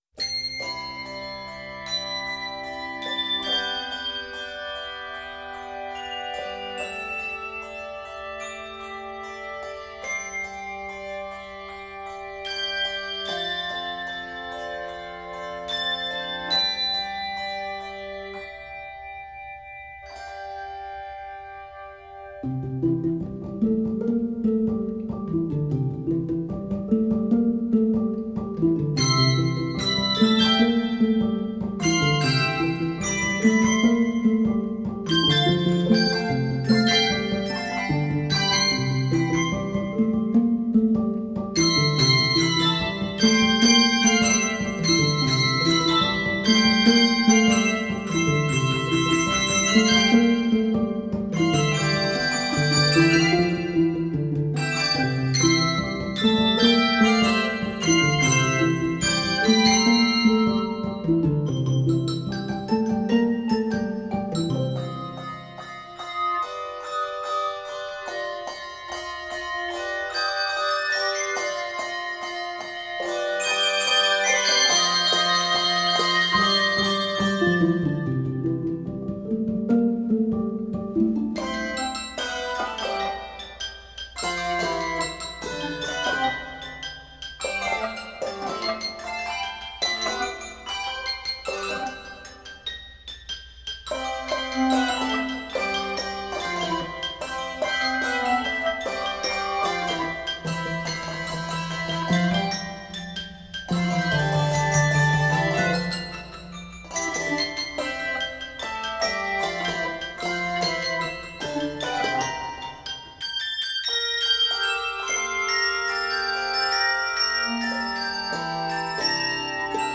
Scored for 3–5 octave handbells.
Key of C Major.